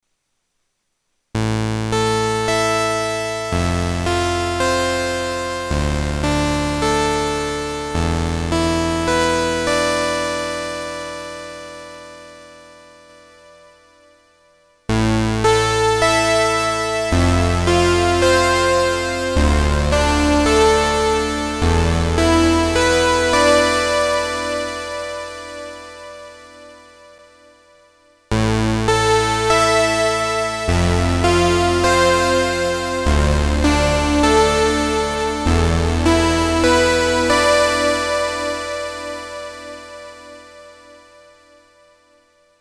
Сегодня в гостях System 8 с эмуляцией Juno 60. Сухой сигнал, Juno Chorus 1, Juno Chorus 2 Saw+Sub Saw+Sub+Pwm Вложения S8-Juno60-SawSub.mp3 S8-Juno60-SawSub.mp3 624,6 KB · Просмотры: 1.593 S8-Juno60-SawSubPwm.mp3 S8-Juno60-SawSubPwm.mp3 641,6 KB · Просмотры: 1.591